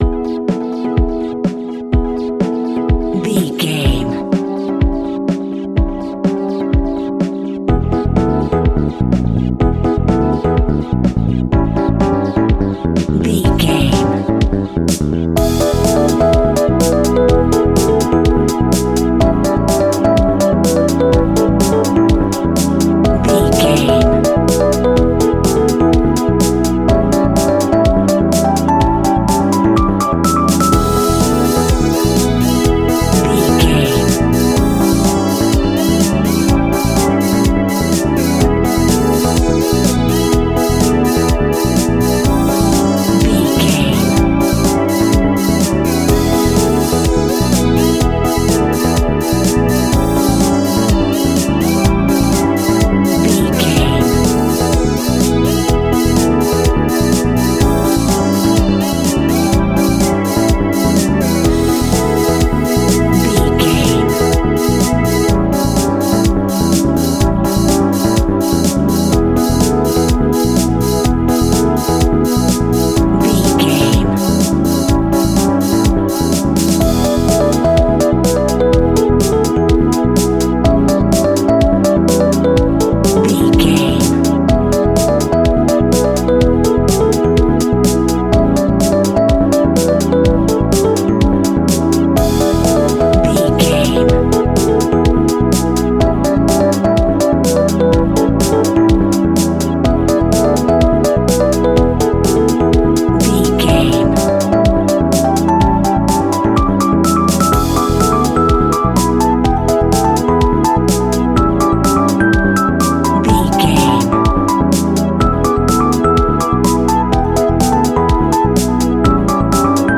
Aeolian/Minor
groovy
uplifting
bouncy
playful
drums
synthesiser
electric piano
electric guitar
funky house
upbeat
synth bass